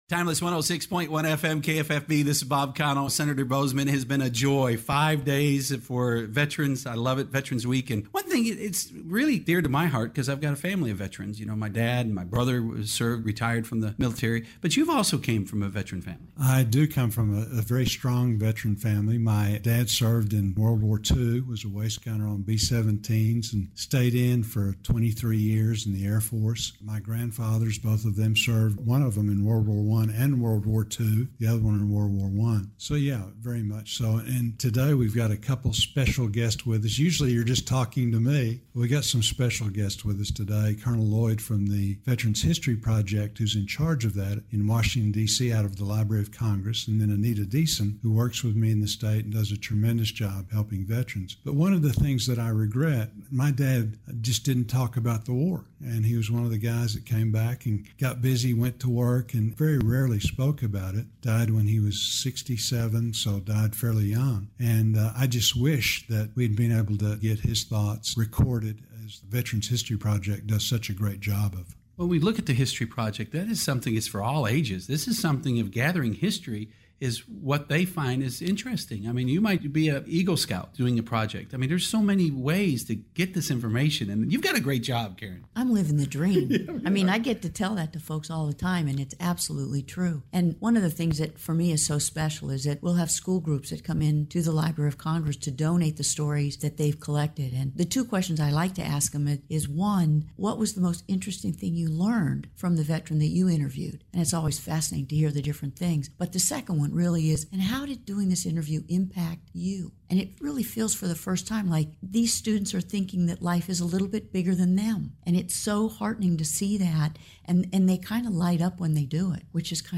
KFFBs-Open-Mic-with-Senator-John-Boozman-Day-5-2019.mp3